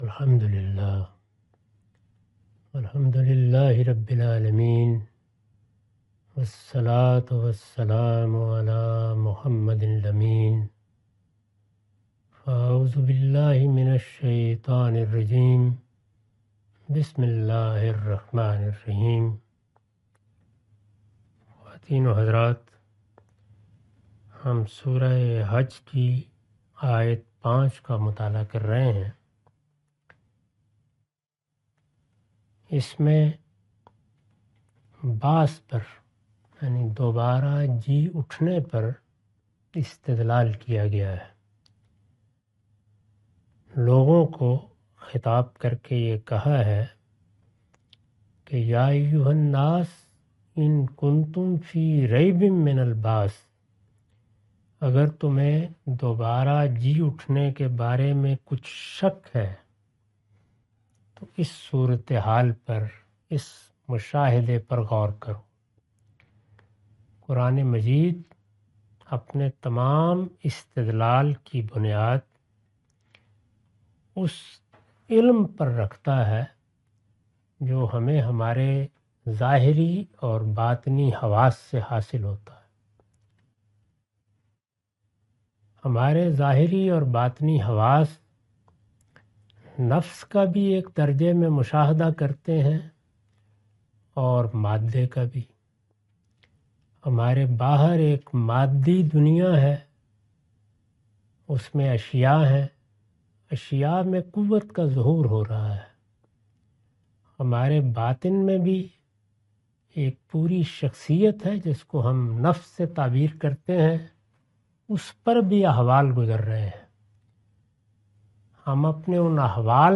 Surah Al-Hajj A lecture of Tafseer-ul-Quran – Al-Bayan by Javed Ahmad Ghamidi. Commentary and explanation of verse 05.